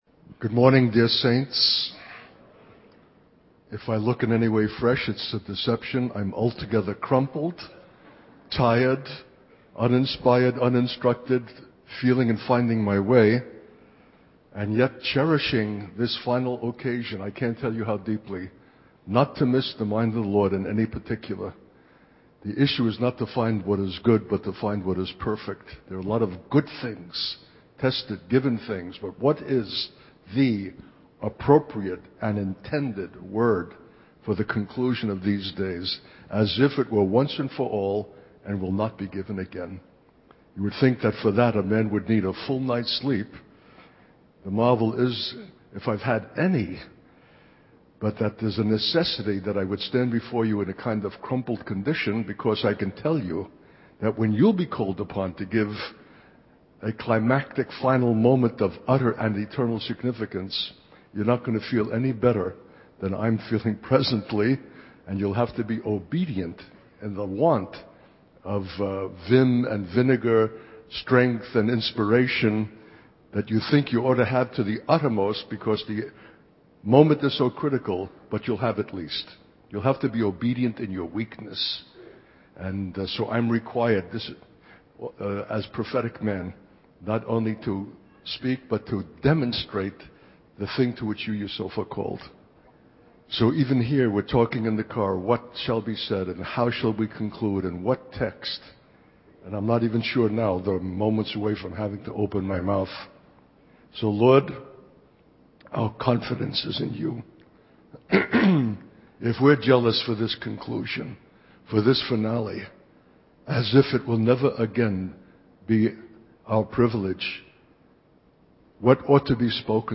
In this sermon, the speaker emphasizes the importance of obedience and weakness in fulfilling God's calling. He acknowledges his own tiredness and lack of inspiration, but recognizes the critical nature of the moment and the need to be obedient even in weakness.